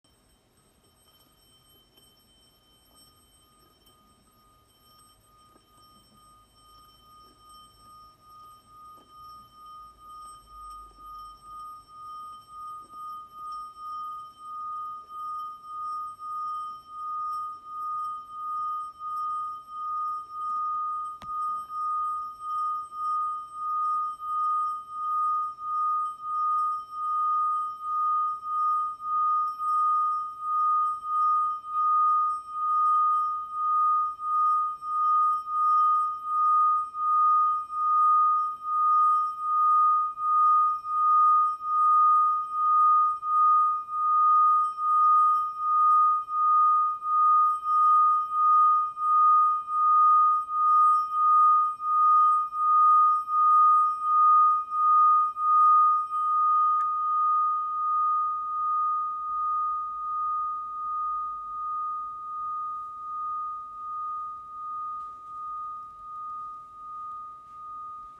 Ecoutez les vibrations du bol japonais Zenkoan de 8 cm avec un bâton simple en bois de 18 cm:
Les vibrations régulières se produisent au bout de 30 sec environ
vibration-bol-chantant-japonais.m4a